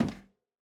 added stepping sounds
Flats_Metal_Grate_001.wav